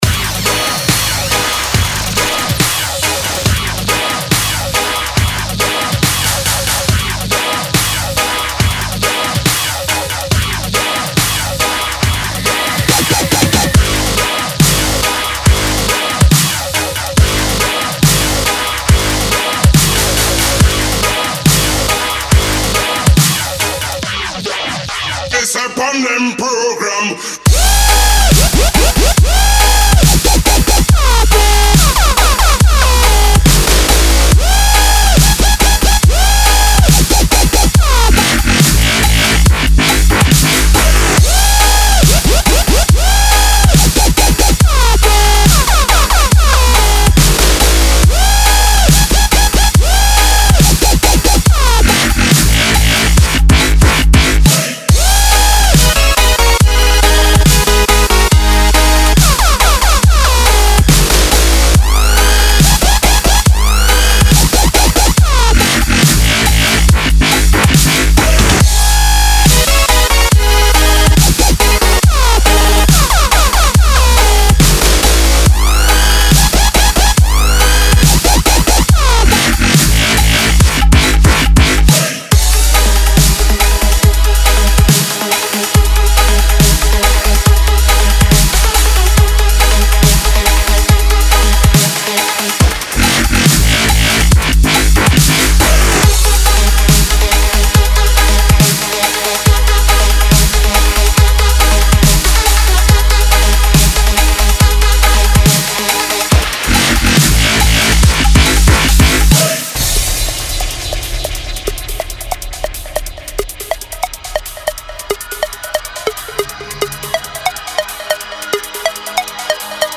Стиль музыки: Dub Step